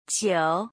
台湾と中国と同じ発音・同じ中国語の発音です。